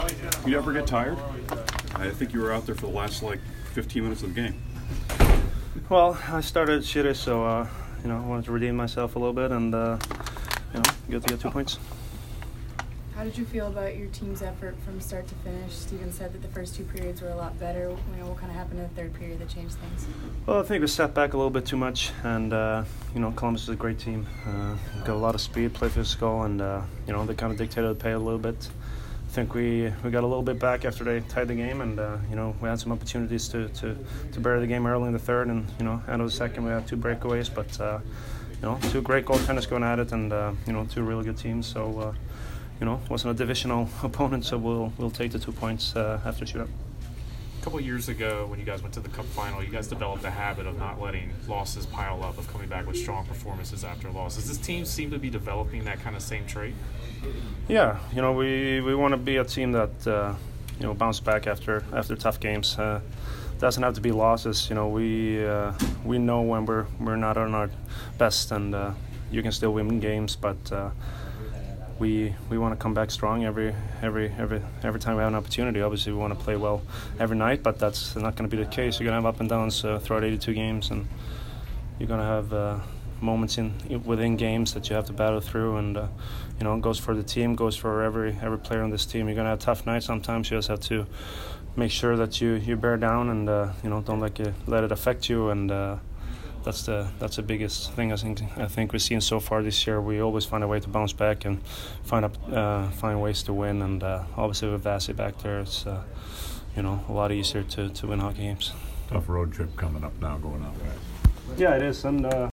Victor Hedman Post-Game 11/4